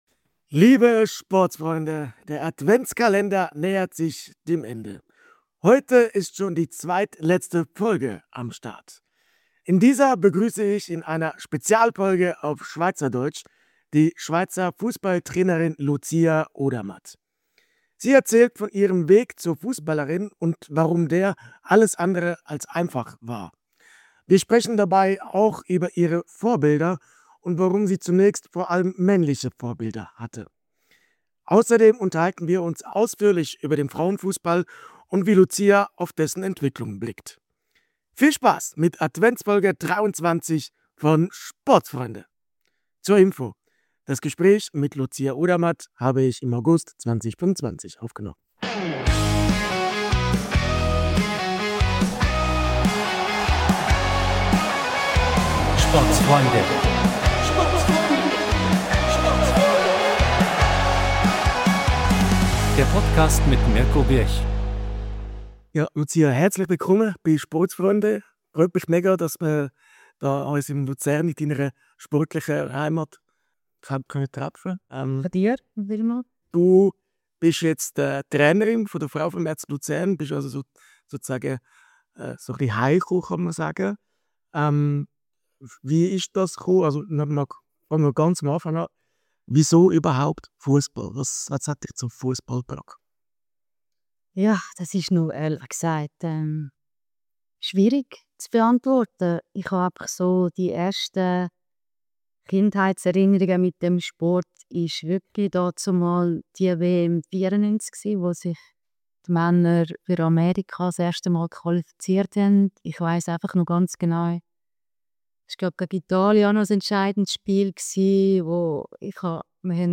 Heute begrüssen wir in einer SPEZIALFOLGE auf SCHWEIZERDEUTSCH